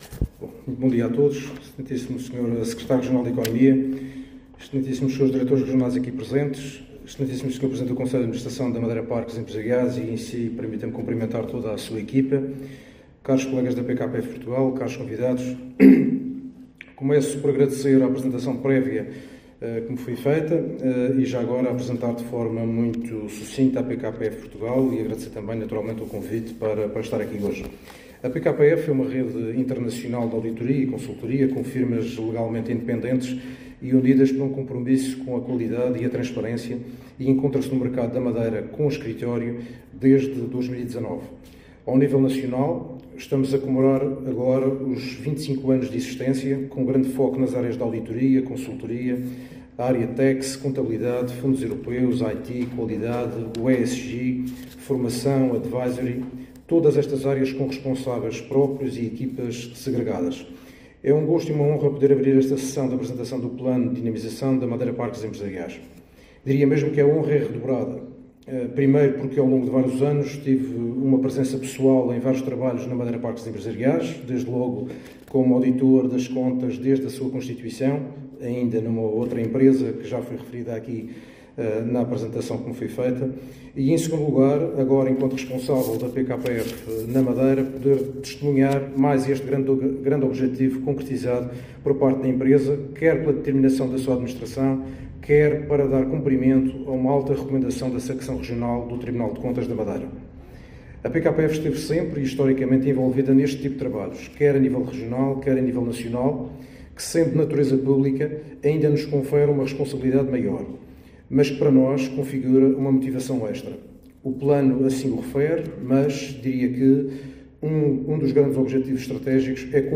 A apresentação do Plano de Dinamização dos Parques Empresariais 2025-2031 serviu de mote para o Secretário Regional da Economia revelar os últimos indicadores.
Apresentação Plano Dinamização MPE_Abertura.mp3